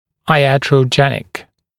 [aɪˌætrəuˈdʒenɪk][айˌэтроуˈдженик]ятрогенный